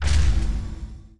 ui_interface_66.wav